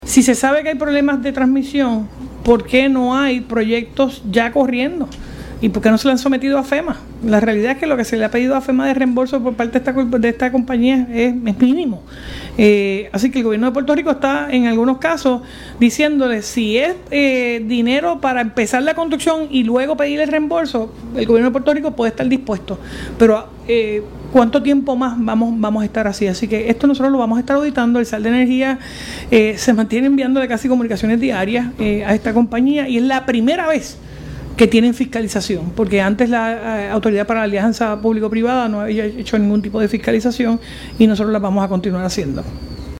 A preguntas de la prensa en una conferencia sobre si entendía que es una manera en la que LUMA está preparando el camino para solicitar un aumento o la llamada tarifa de emergencia, González Colón aseguró que “todo el mundo tiene sus deseos en la vida“, dijo en referencia al pedido al destacar que el Gobierno hizo un mal negocio con la empresa.
325-JENNIFFER-GONZZLEZ-GOBERNADORA-CUESTIONA-PORQUE-LUMA-NO-HA-COMENZADO-PROYECTOS-PARA-PEDIR-REEMBOLSOS-A-FEMA.mp3